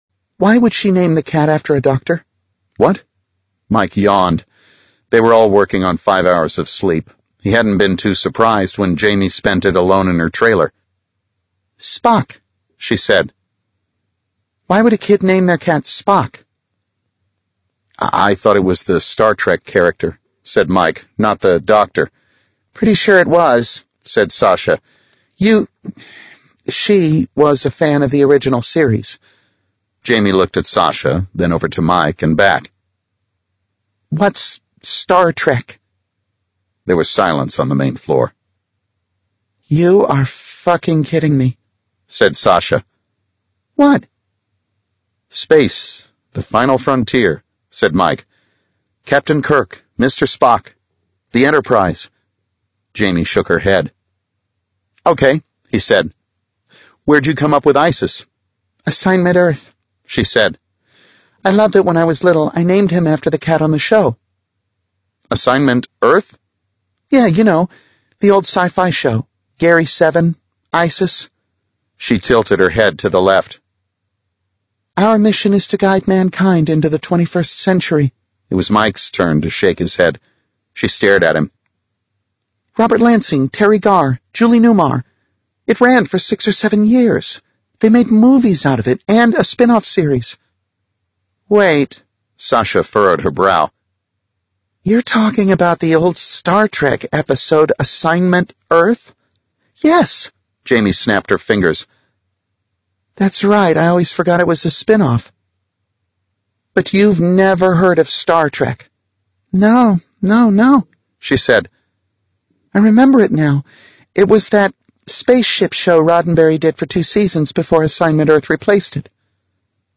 It’s an excerpt from the a-book of The Fold by Peter Clines.